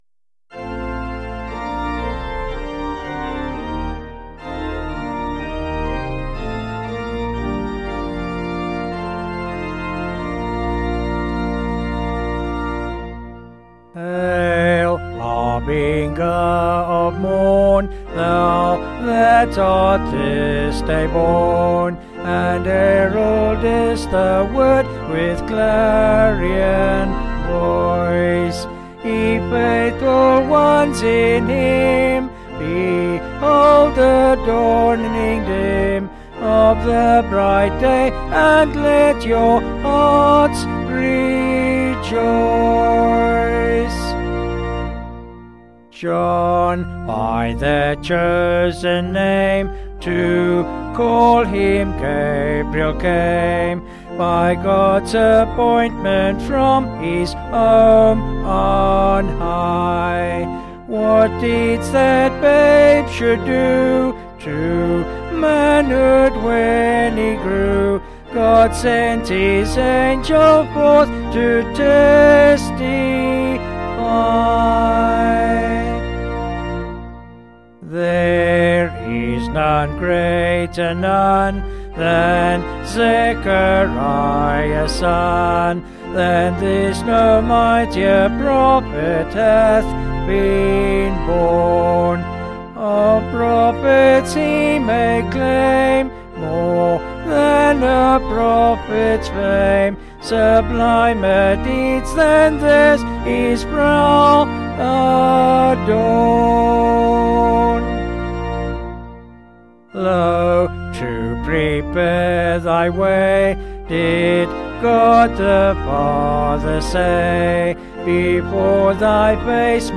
(BH)   5/Bb
Vocals and Organ   705kb Sung Lyrics